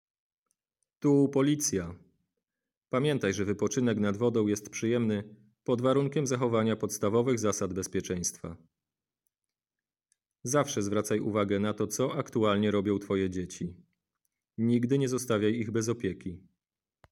Opis nagrania: Komunikaty, które będą nadawane przez system nagłaśniający w radiowozach, w trakcie wizyt policjantów nad wodą.